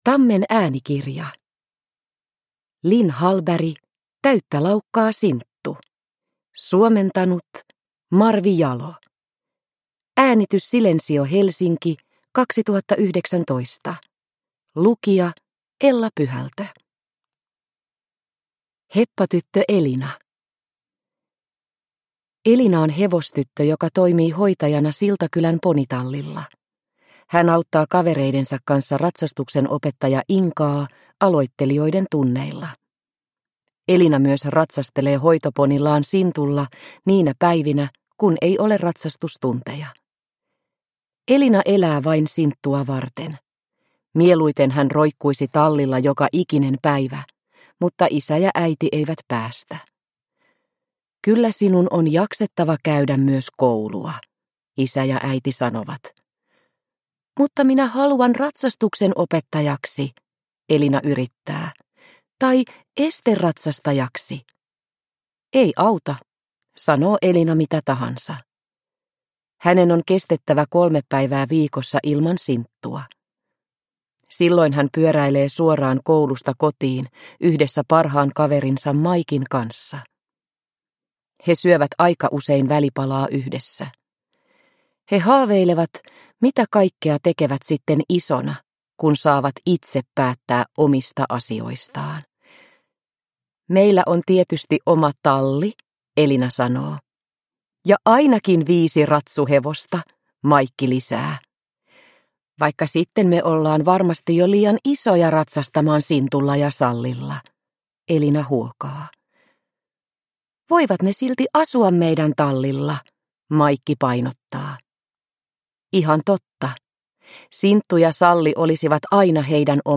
Täyttä laukkaa, Sinttu! – Ljudbok – Laddas ner